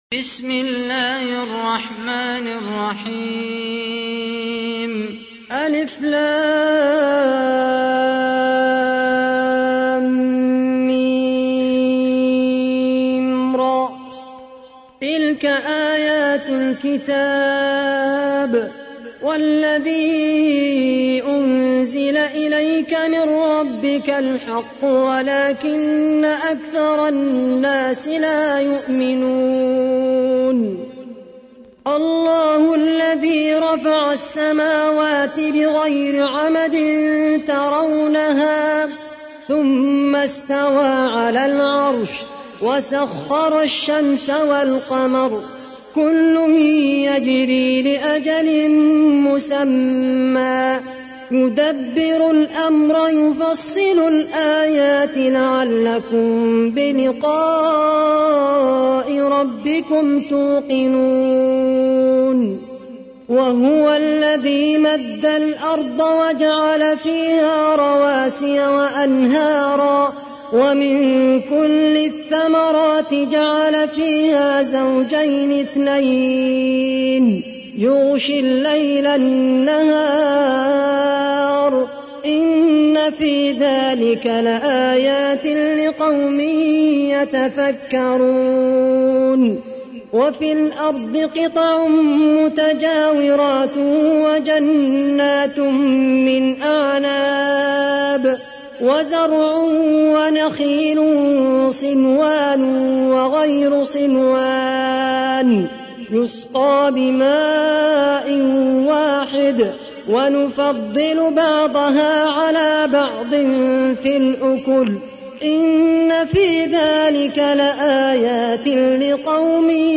تحميل : 13. سورة الرعد / القارئ خالد القحطاني / القرآن الكريم / موقع يا حسين